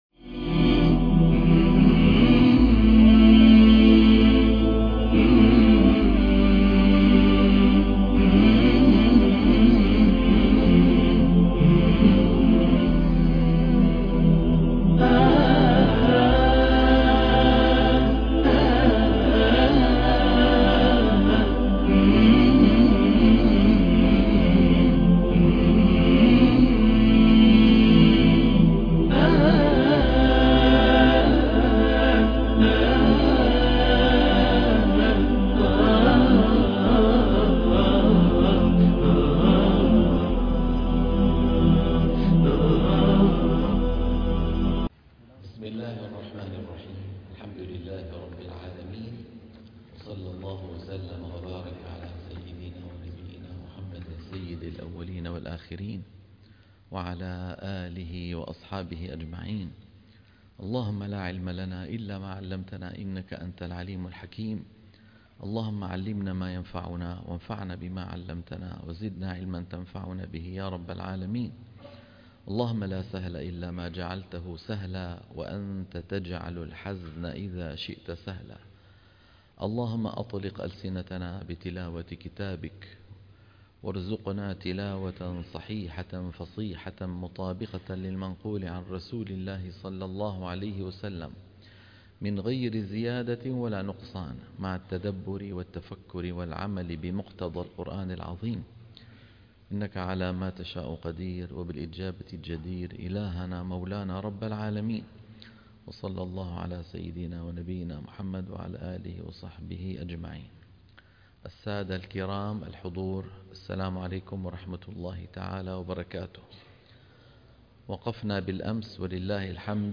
تصحيح التلاوة الحلقة - 62 - تصحيح التلاوة تلقين الصفحة 226